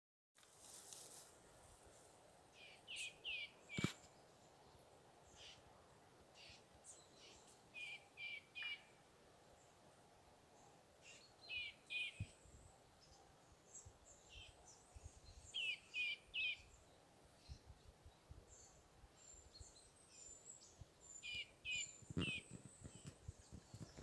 Birds -> Warblers ->
Wood Warbler, Phylloscopus sibilatrix
Administratīvā teritorijaIecavas novads
StatusRecently fledged young (nidicolous species) or downy young (nidifugous species)